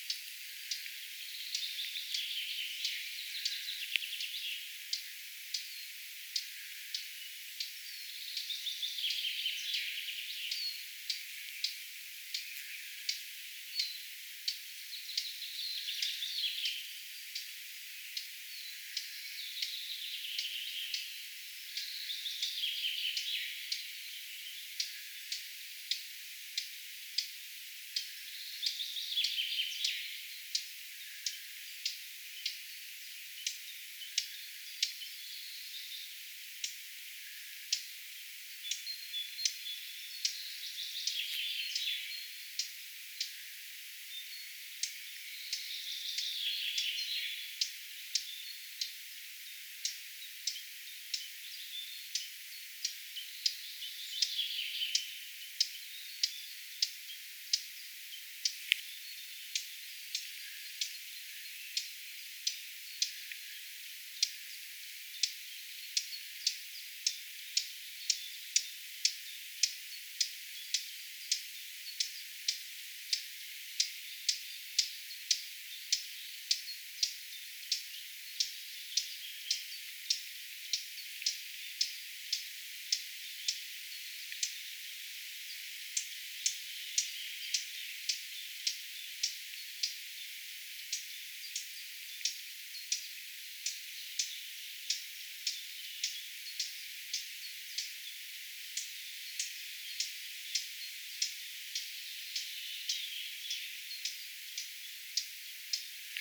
tietääkseni hernekertun huomioääntelyä
tietaakseni_hernekertun_huomioaantelya.mp3